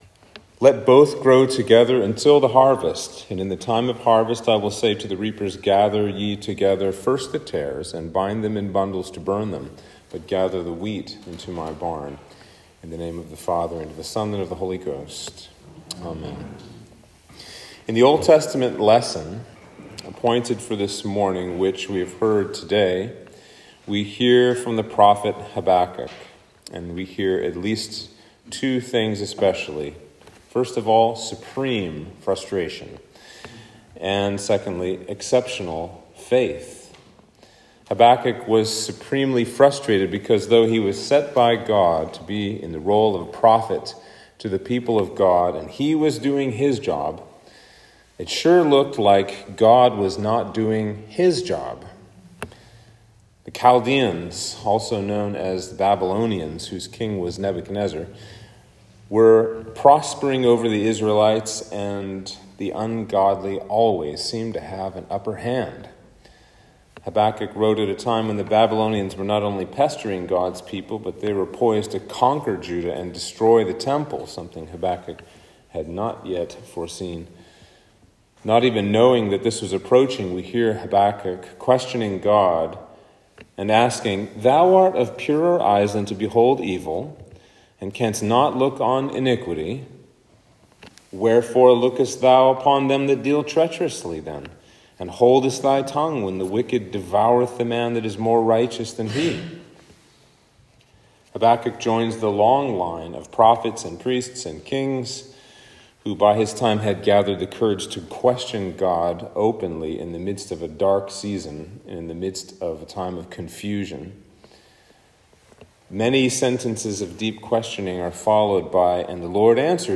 Sermon for Epiphany 5